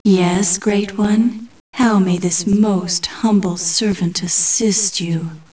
COMPUTER VOICE FILES